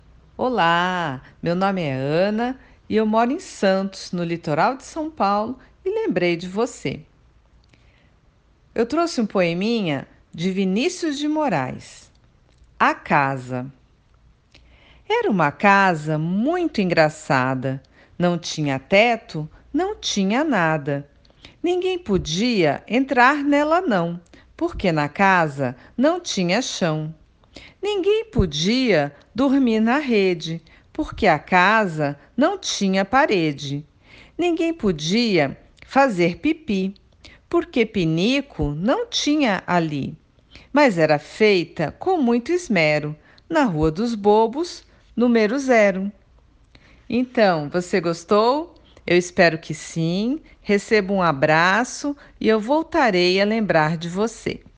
Poesia Português